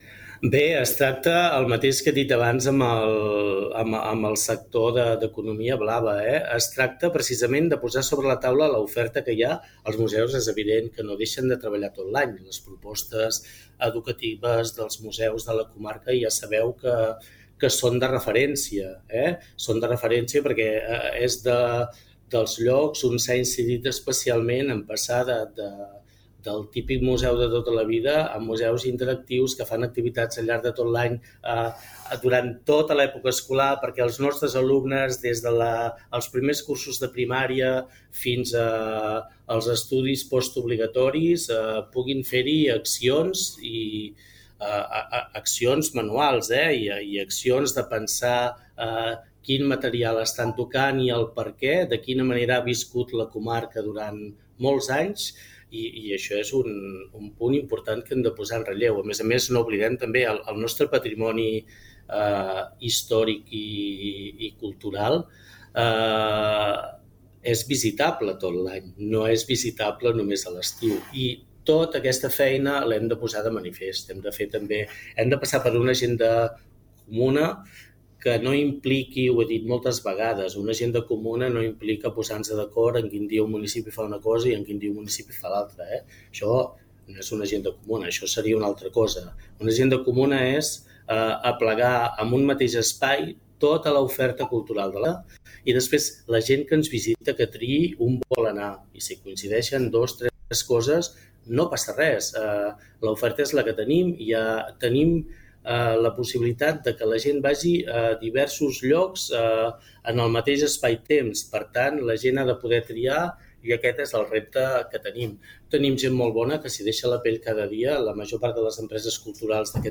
En una entrevista a Ràdio Capital, ha detallat els reptes principals del seu mandat: impulsar l’economia blava, consolidar un hub cultural comarcal, millorar la mobilitat i afrontar la gestió dels residus a la comarca.